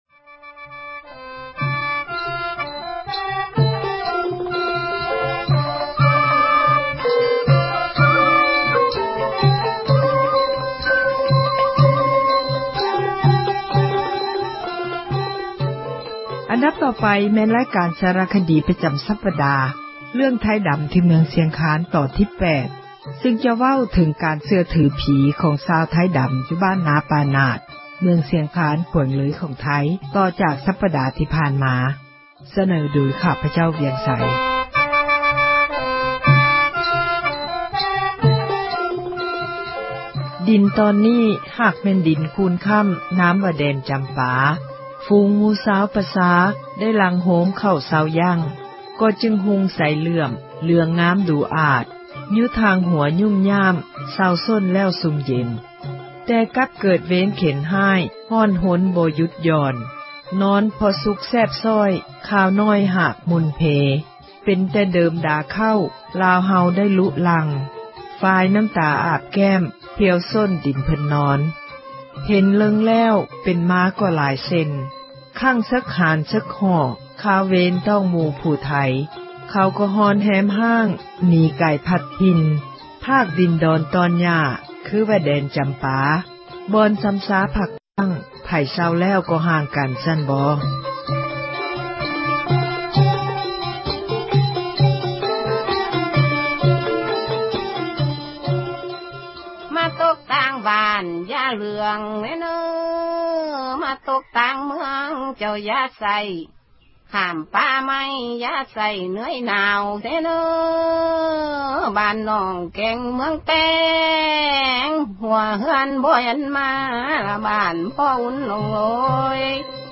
ສາຣະຄະດີ ປະຈຳ ເຣື້ອງ ”ໄທດຳ ທີ່ເມືອງ ຊຽງຄານ” ຕອນທີ 8 ຈະໄດ້ສືບຕໍ່ ເລົ່າເຖິງ ການເຊື່ອຖືຜີ ຂອງຊາວ ໄທດຳ ຢູ່ ບ້ານນາ ປ່ານາດ ເມືອງ ຊຽງຄານ ແຂວງ ເລີຍ ຂອງໄທ.